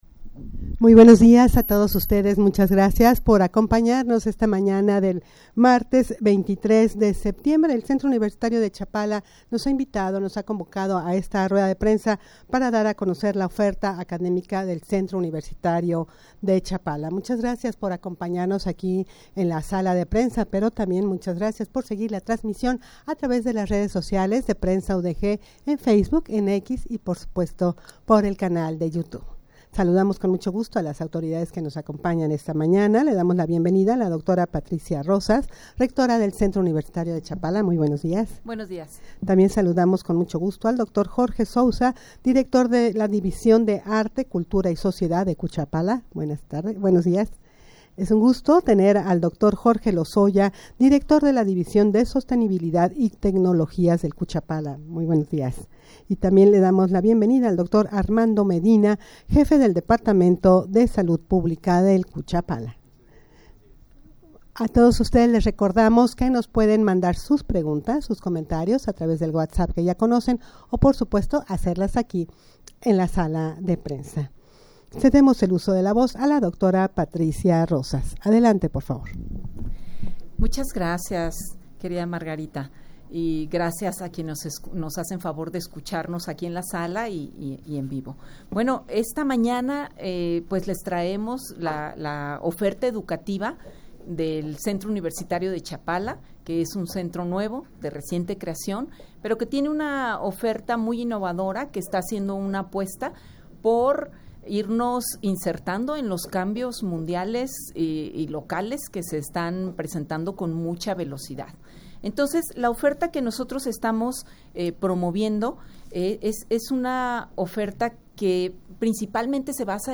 Audio de la Rueda de Prensa
rueda-de-prensa-para-dar-a-conocer-la-oferta-academica-del-centro-universitario-de-chapala.mp3